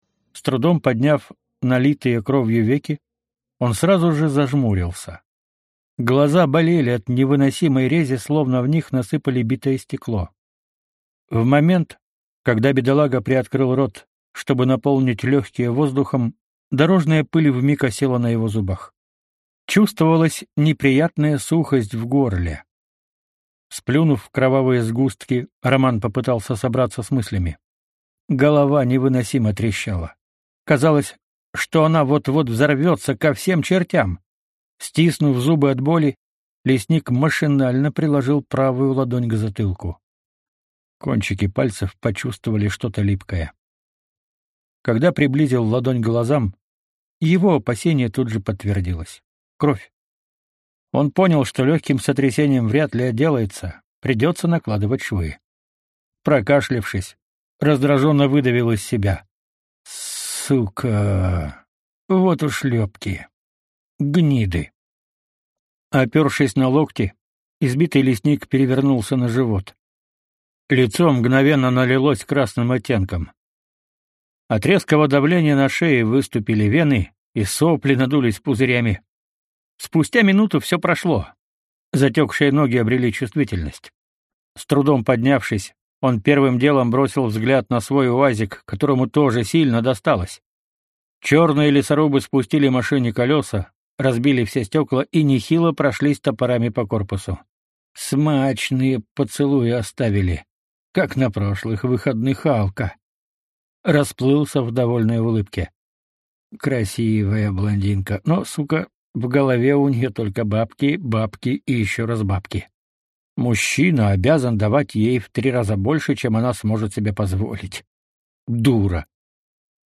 Аудиокнига Миры Артёма Каменистого. S-T-I-K-S. Леший | Библиотека аудиокниг